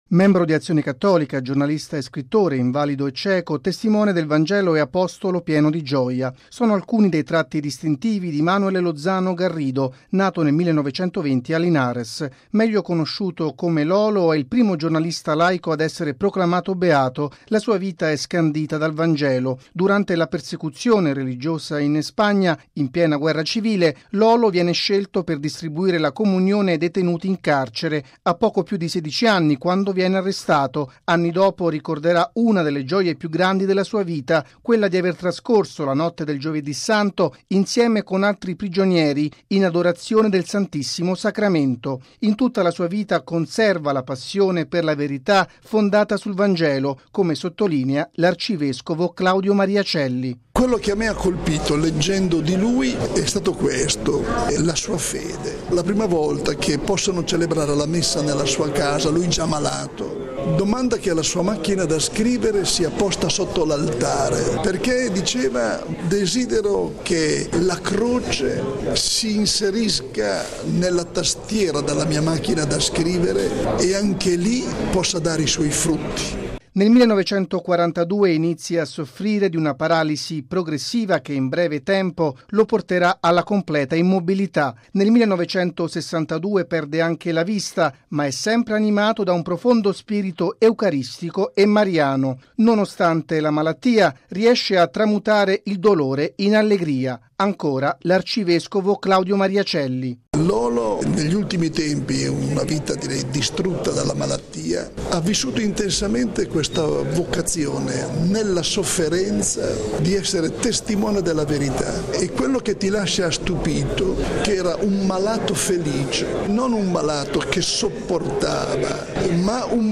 Tra i prossimi Beati c’è, dunque, anche Manuel Lozano Garrido, la cui straordinaria figura è stato presentata stamani, nella sede della nostra emittente, dal presidente del Pontificio Consiglio delle Comunicazioni Sociali, l’arcivescovo Claudio Maria Celli.